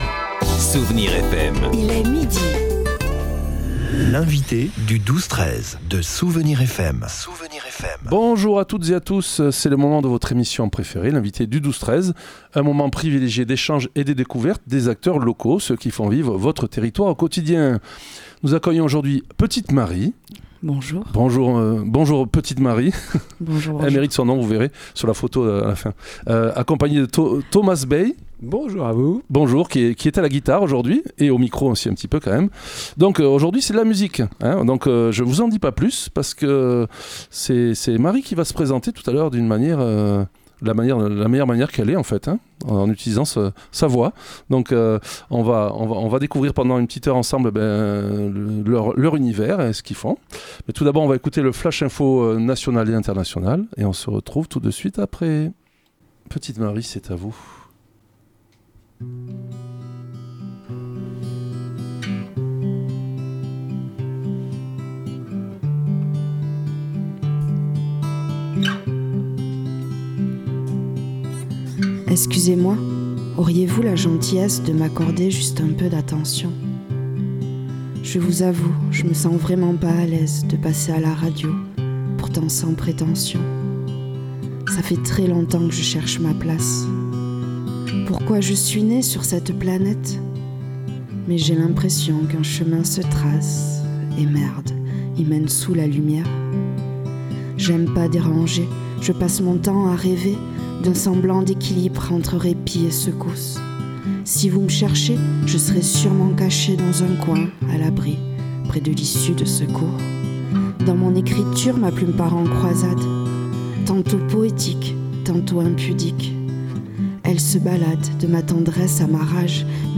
Une douce rencontre avec une petite voix timide qui tout à coup prends de l'ampleur, du volume et de l'intensité pour presque crier pourquoi elle existe.
Ecoutez-nous, on a causé, on a ri et on a fait de la musique, quoi de plus joyeux ?